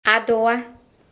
pronunciation of "Adowa" (help·info)) yɛ asa a Akanfoɔ a wɔwɔ Ghana sa.
Tw-Adowa.ogg